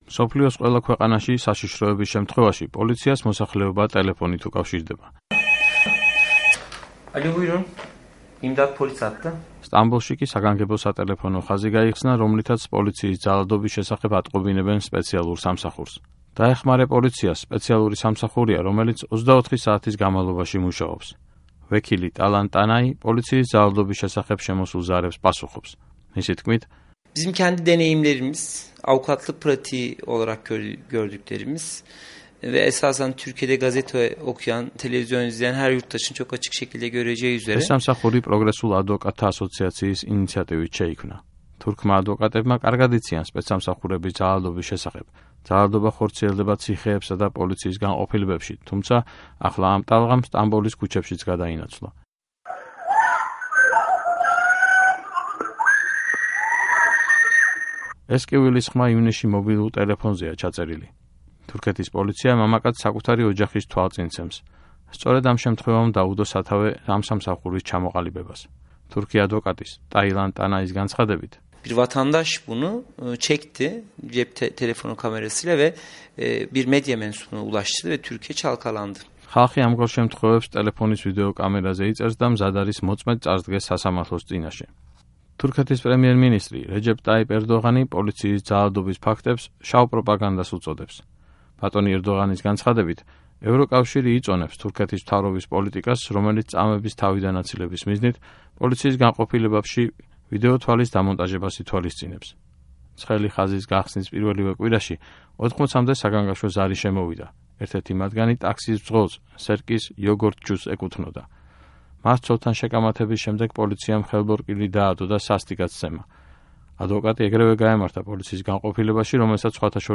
ეს კივილის ხმა ივნისში მობილურ ტელეფონზეა ჩაწერილი.